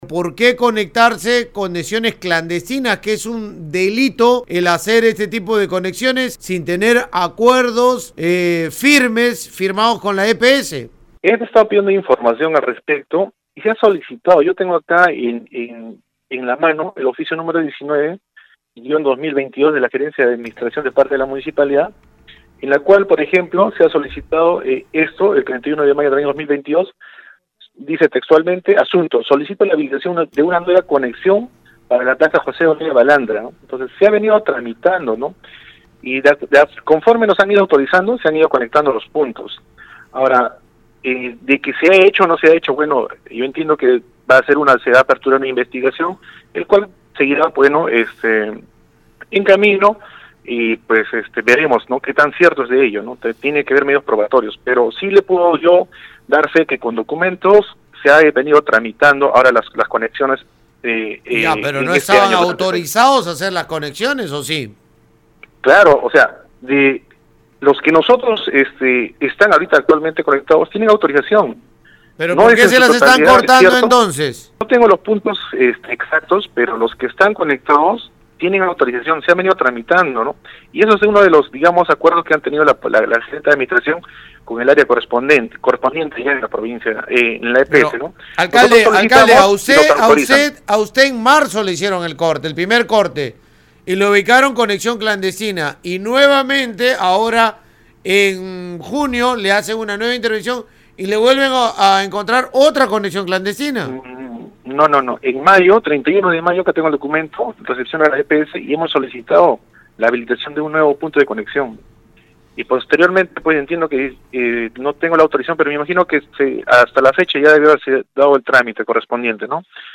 En conversación con Radio Uno, el alcalde Helmer Fernández refirió que el 31 de mayo fue enviado a EPS el oficio 19-2022 solicitando  nueva conexión para la plaza José Olaya Balandra que abastece al recinto edil, tratando así de negar que la tubería adicional hallada por EPS sea ilegal.